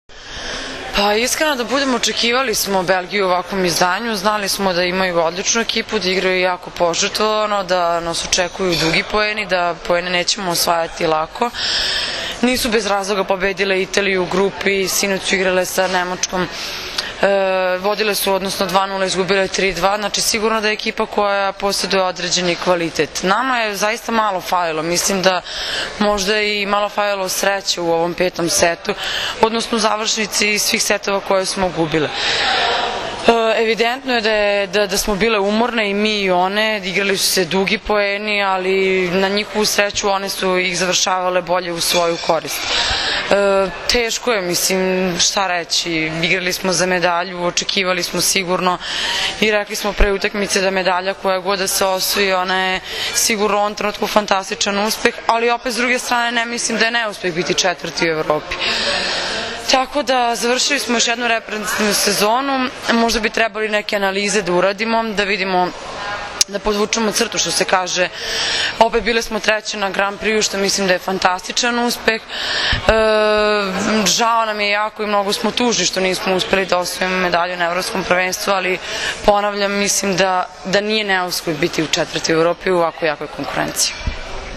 IZJAVA MAJE OGNJENOVIĆ